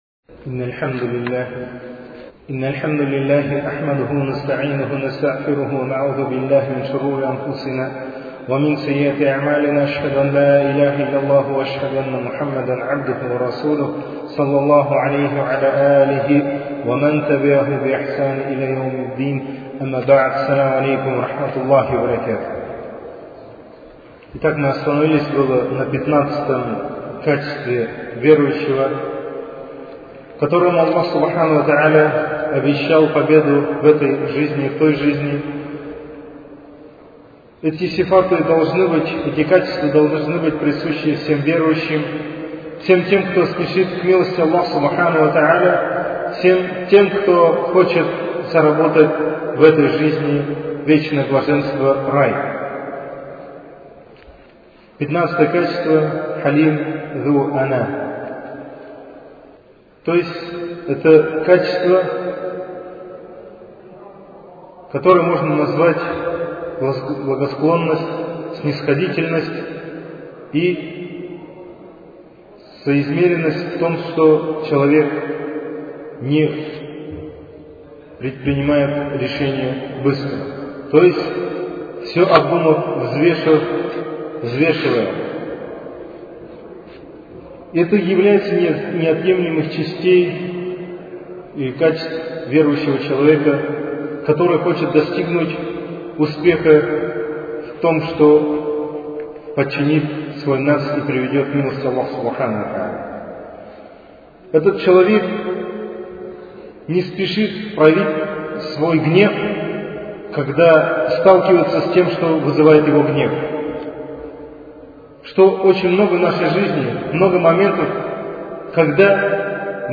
سلسلة محضرات